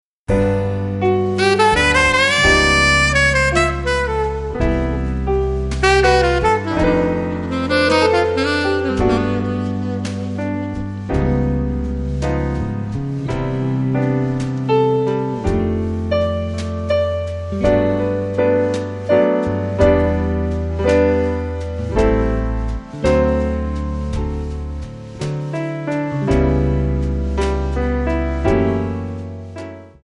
MPEG 1 Layer 3 (Stereo)
Backing track Karaoke
Pop, Oldies, Jazz/Big Band, 1950s